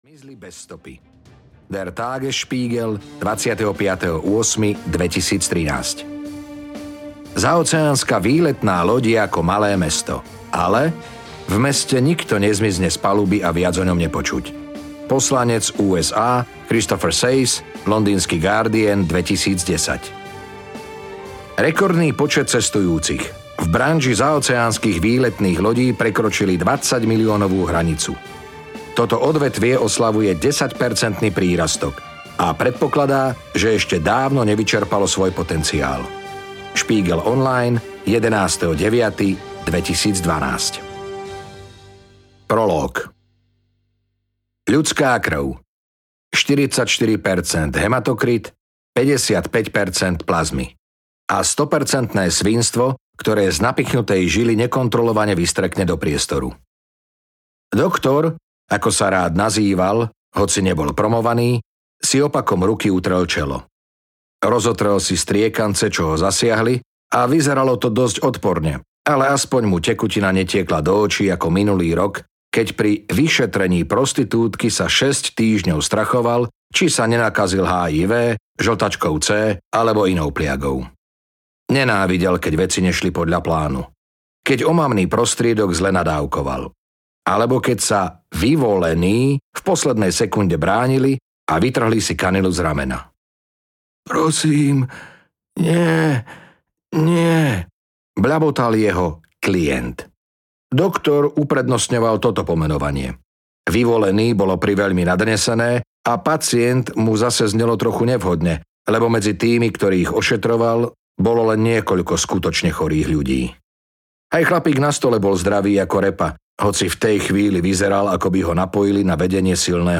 Pasažier 23 audiokniha
Ukázka z knihy